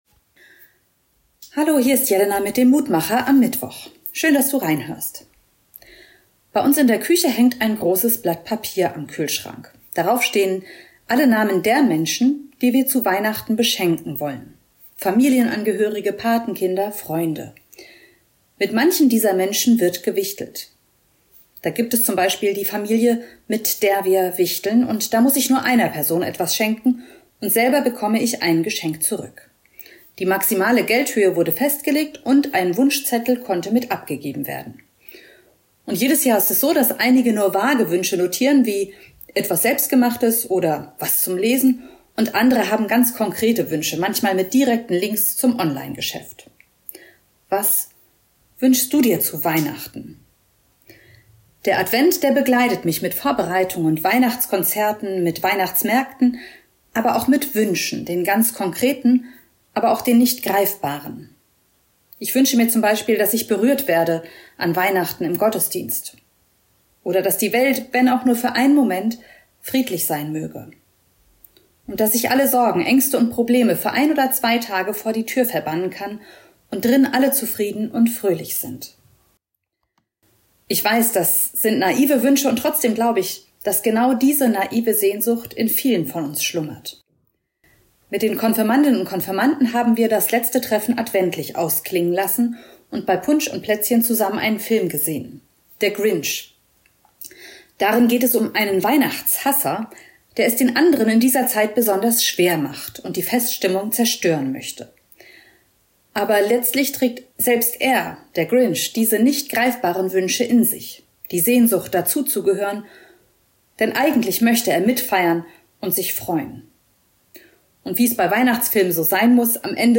Mutmacher - Kleine Andacht zum Tag